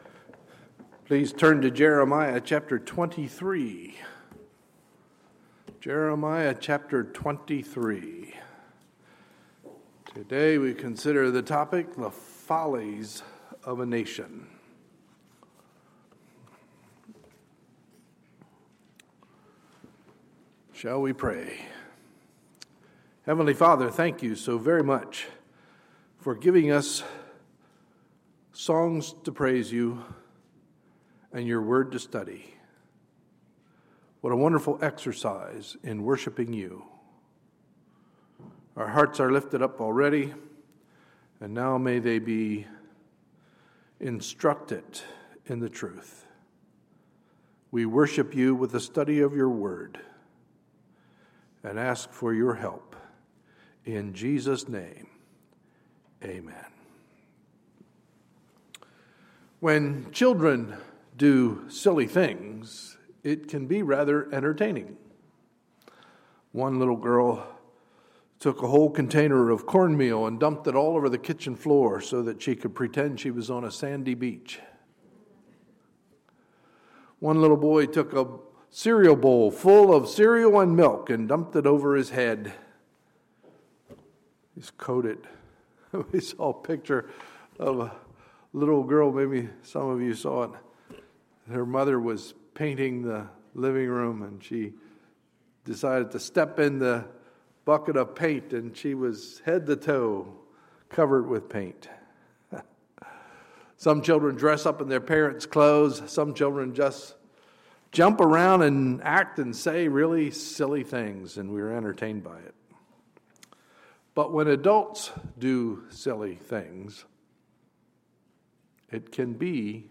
Sunday, July 12, 2015 – Sunday Morning Service